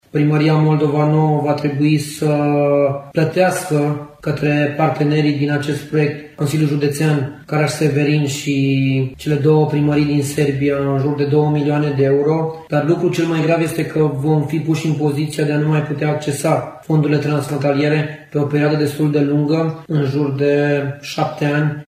Primarul Adrian Torma a declarat pentru Radio Reşiţa că ia în calcul şi o eventuală retragere a sa din funcţie.